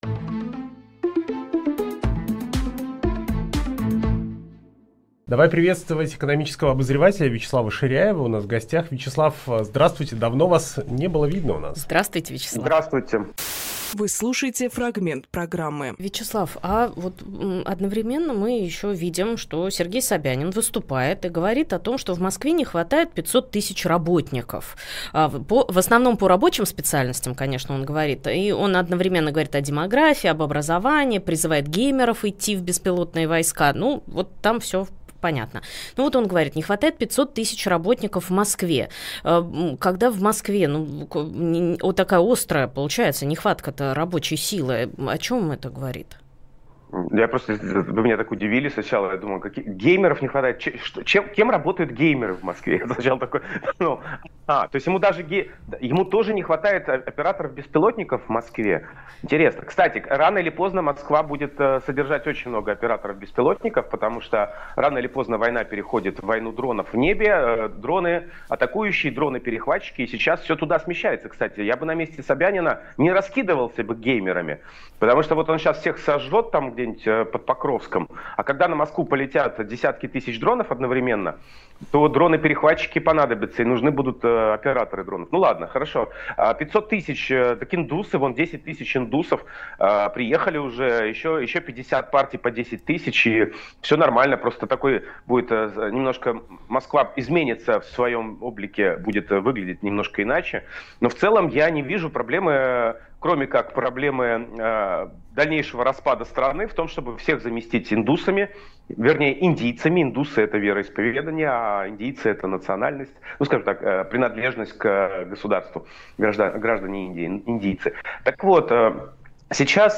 Фрагмент эфира от 25.12.25